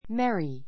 merry A1 méri メ リ 形容詞 比較級 merrier mériə r メ リア 最上級 merriest mériist メ リエ スト 楽しい , 愉快 ゆかい な, 陽気な a merry song a merry song 陽気な歌 I wish you a merry Christmas!=Merry Christmas!—(The) Same to you!